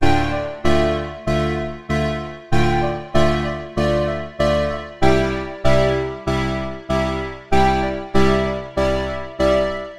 爵士乐合成器
描述：爵士乐合成器
Tag: 96 bpm Electronic Loops Synth Loops 1.68 MB wav Key : F